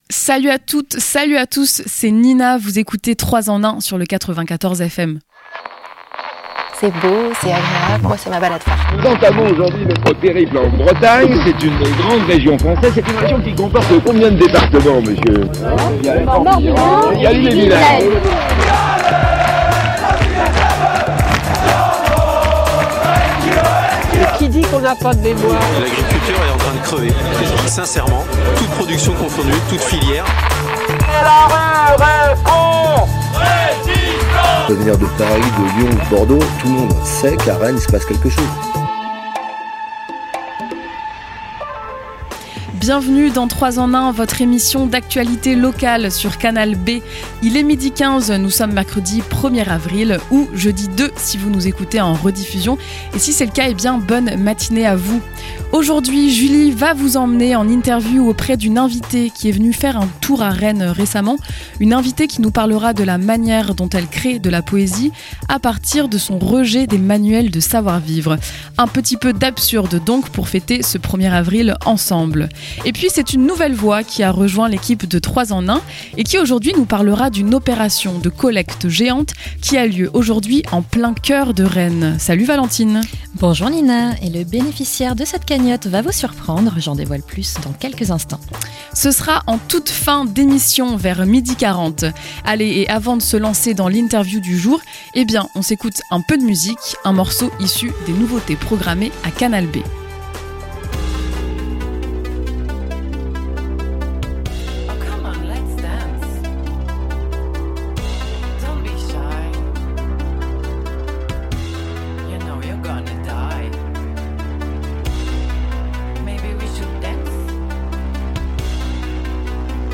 Un ouvrage inspiré des manuels de savoir vivre du XXème siècle. Une interview mêlant absurde, pseudonymes et conseils pour disparaître.